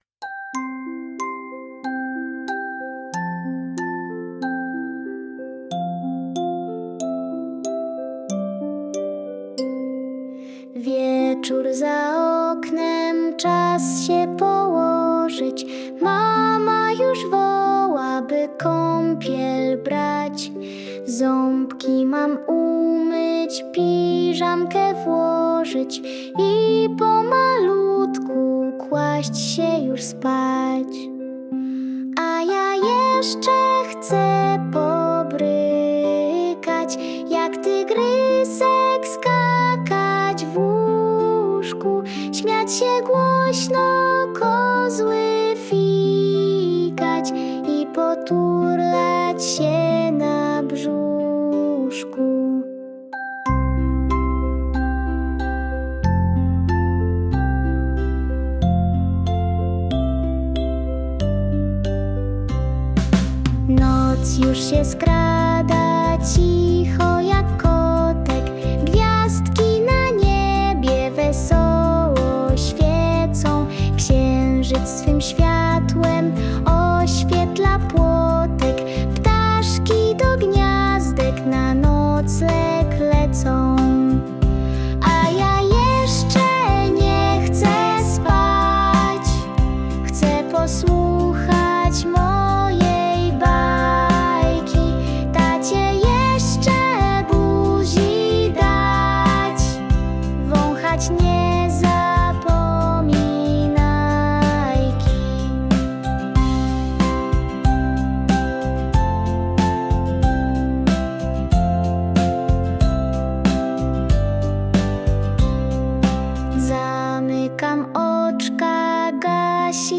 Moja pierwsza piosenka dla dzieci 'A ja nie chcę jeszcze spać!'